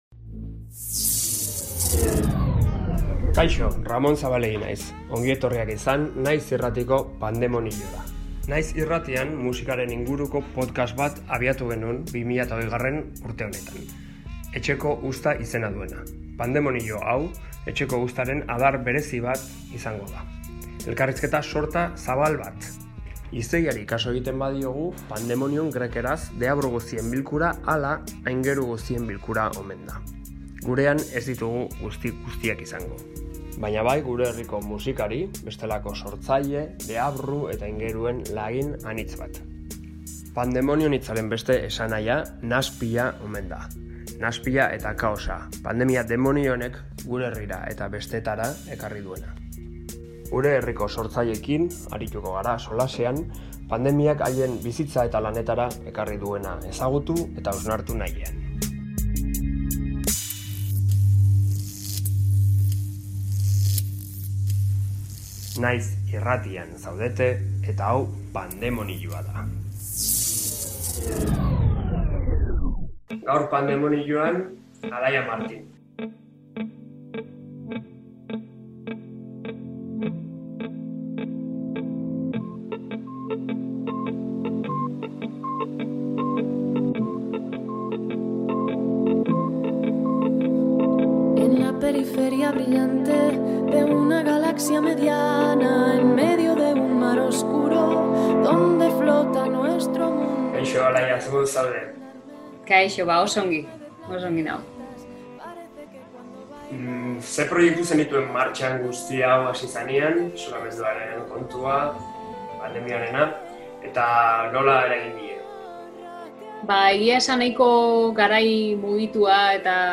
2020an abiatutako ‘Etxeko Uzta’ podcastaren adar bat da ‘Pandemoniyua’. Elkarrizketa sorta zabal bat. Gure herriko sortzaileekin arituko gara solasean, pandemiak haien bizitzara eta lanetara ekarri duena ezagutu eta hausnartzeko.